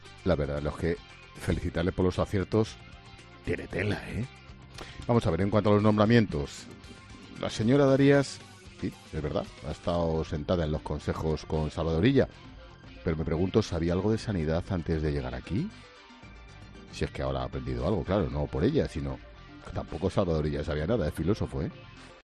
El director de 'La Linterna', Ángel Expósito, se ha referido en estos términos a una de las protagonistas del día